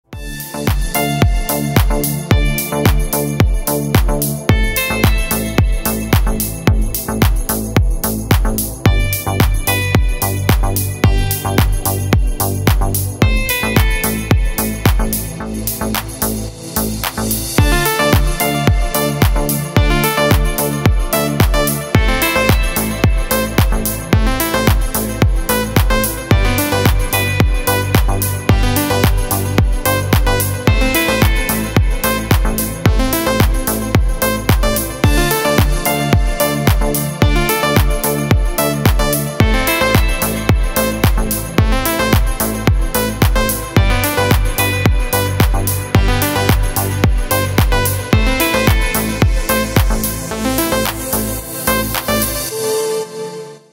Симпатичная мелодия